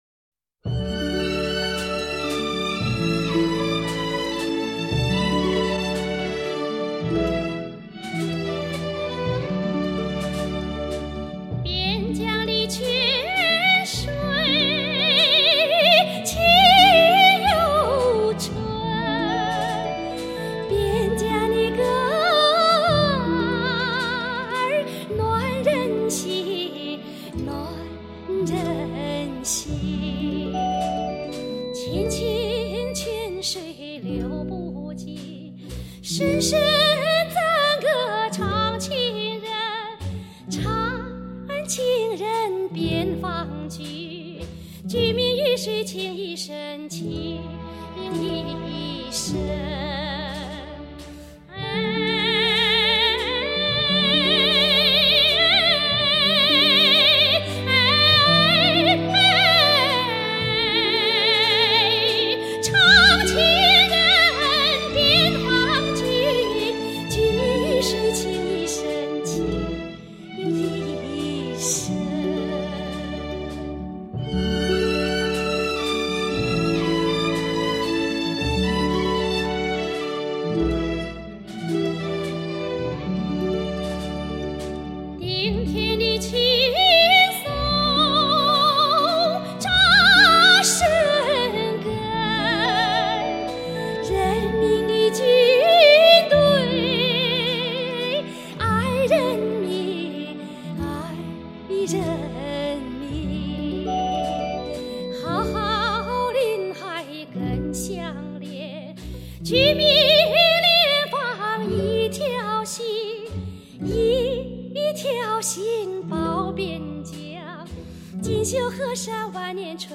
独一无二的魅力唱腔，蕴味浓郁的地方民族风情。